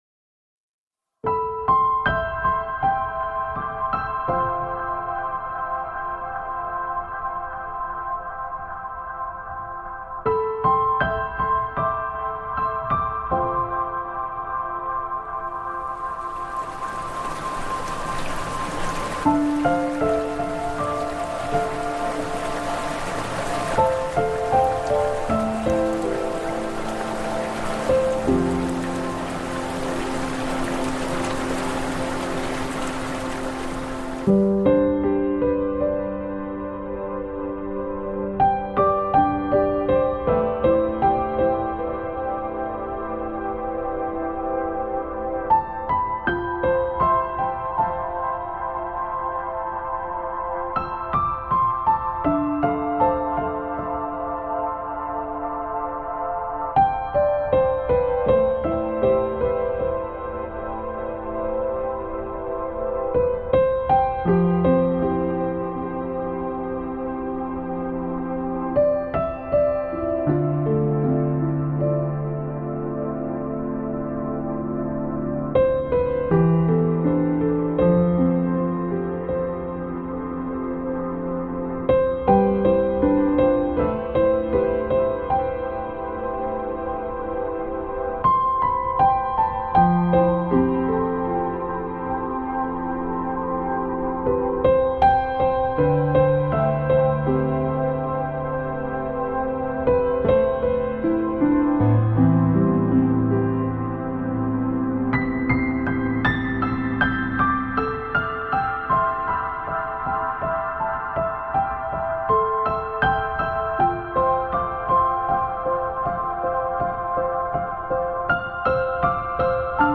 ENTSPANNENDES MORGENLICHT: Pakistanische Täler-Morgenlicht mit unberührter Natur
Naturgeräusche